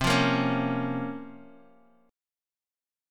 Bbm/C chord
Bb-Minor-C-x,3,3,3,2,x.m4a